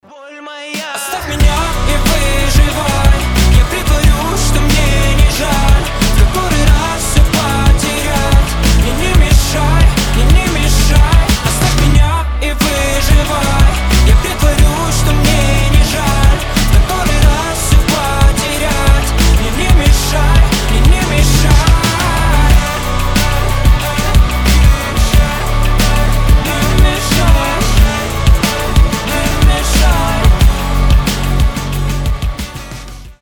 поп , рок
грустные